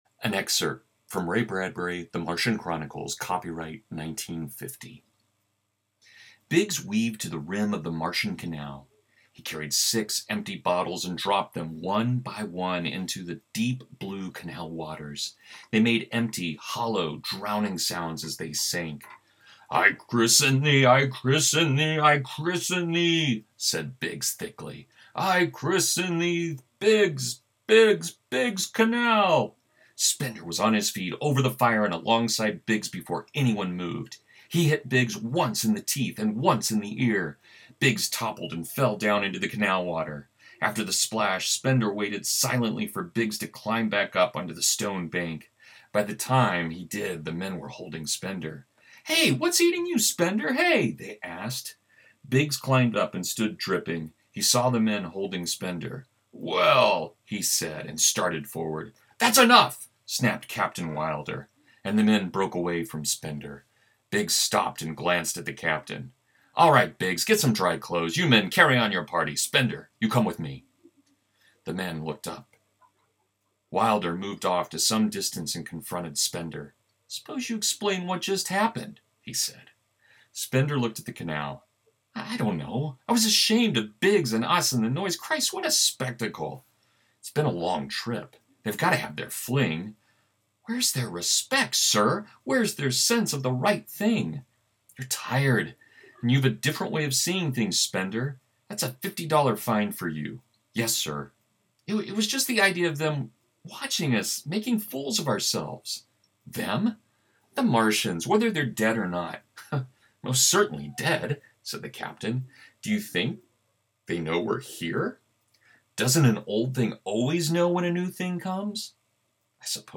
A Reading from Martian Chronicles